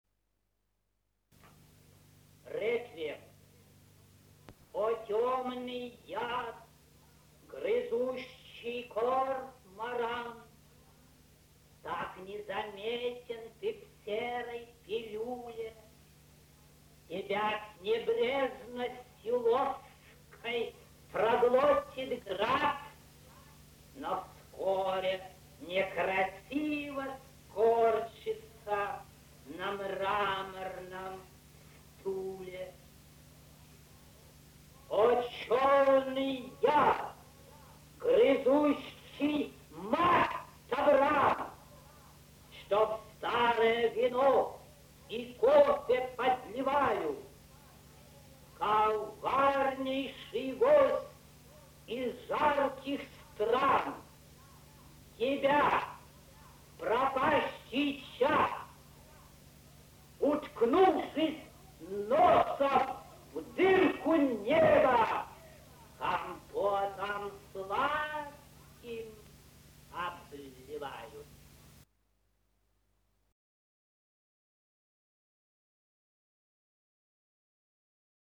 Gravação do autor, Moscou, 1951.